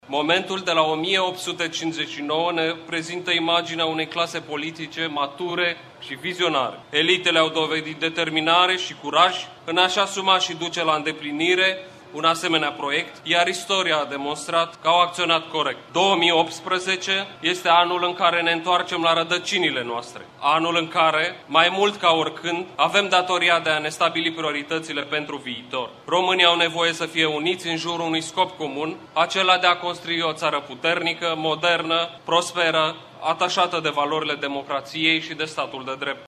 Aproximativ 5.000 de persoane au participat, astăzi, în Piaţa Unirii din Iaşi la manifestările organizate pentru a marca importanţa Unirii de la 1859.
În numele preşedinţiei României, consilierul Andrei Muraru a exprimat opinia că ţara noastră ar putea deveni puternică, modernă şi prosperă şi ataşată de valorile democraţiei şi a statului de drept: